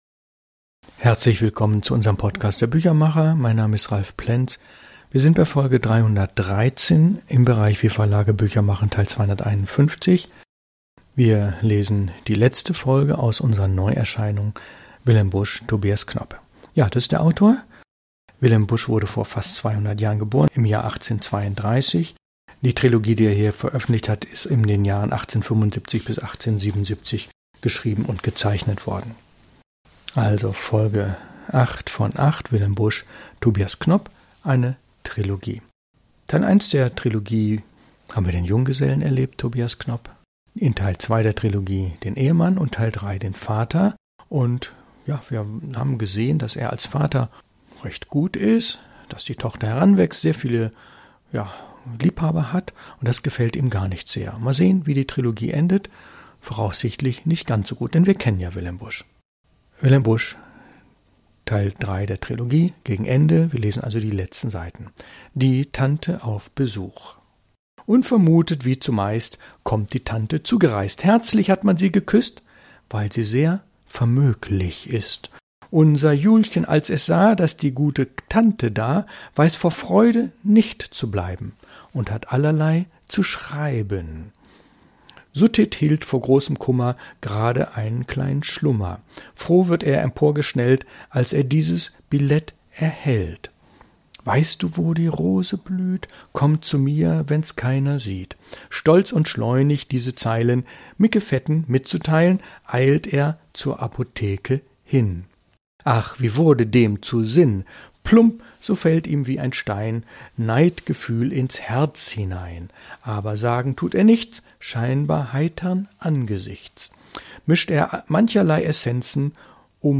Rezitation